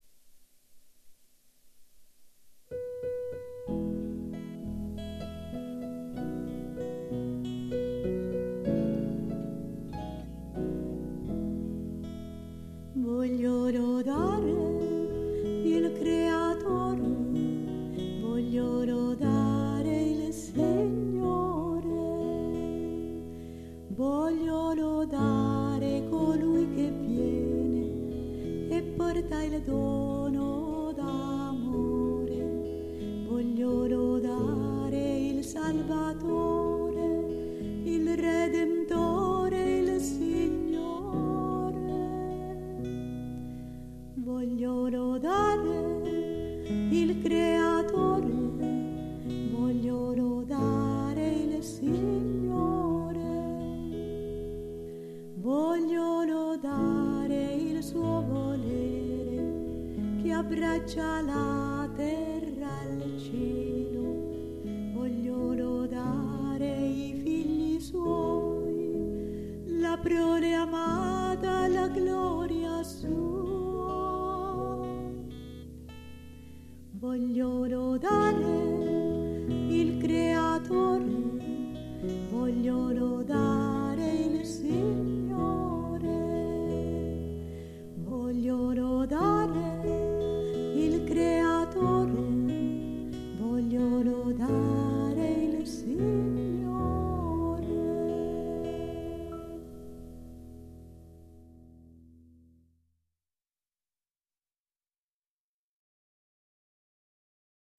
Lode nel Fiat canto mp3